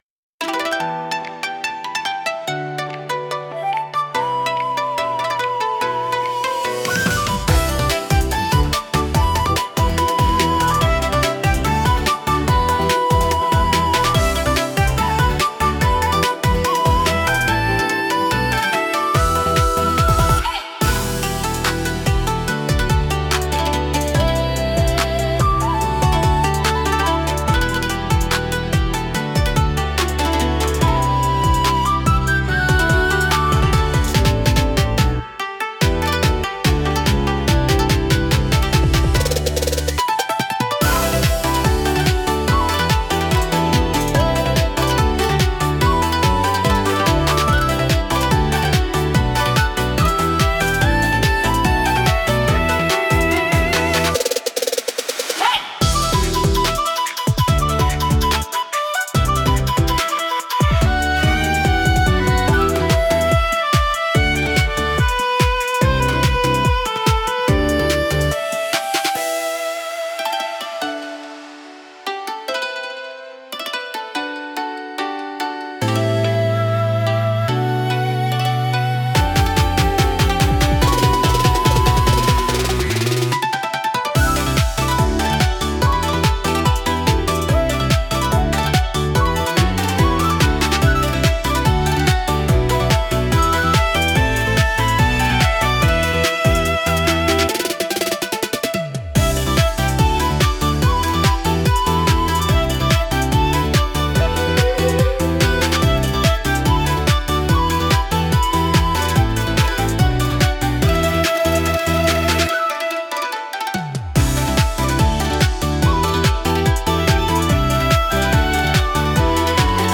琴や尺八、三味線などの日本的な楽器の音色と、ビートやエレクトロニック要素が調和したスタイリッシュな楽曲が特徴です。